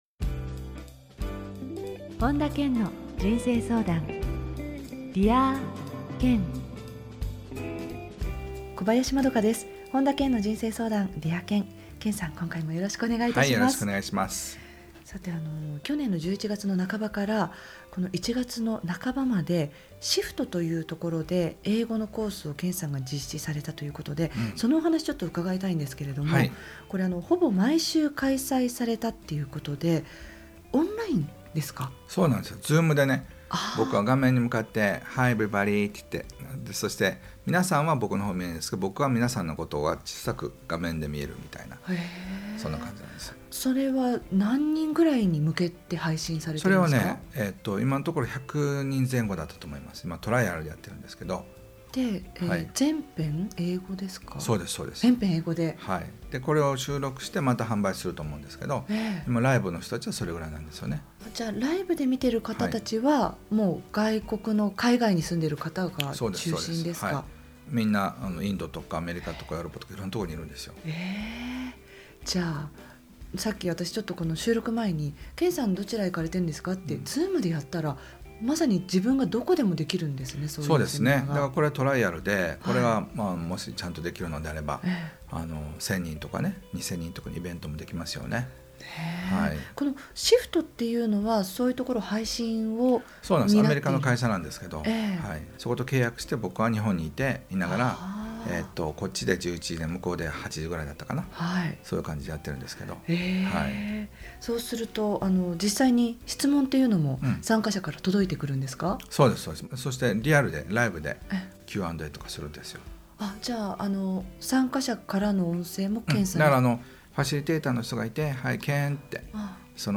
本田健の人生相談 ～Dear Ken～ 傑作選 今回は「多くの人に愛されている人の習慣」をテーマに、本田健のラジオミニセミナーをお届けします。